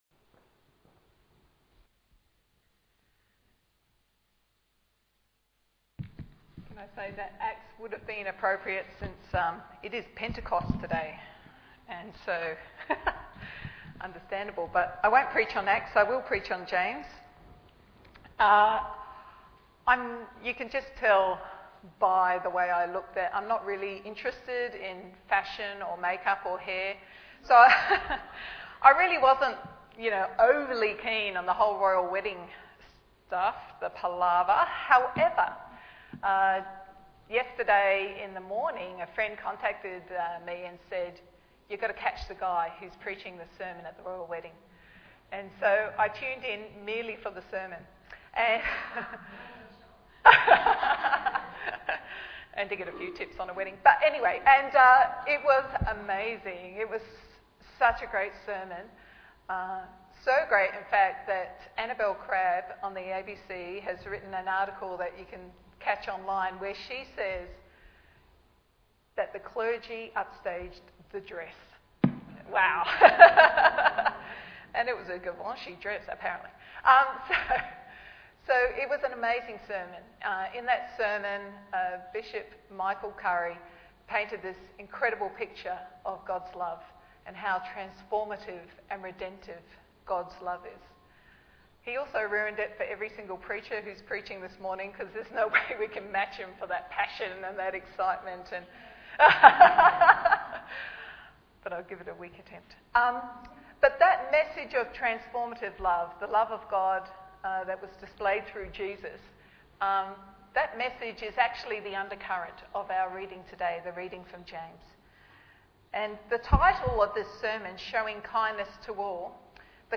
Bible Text: James 2:1-13 | Preacher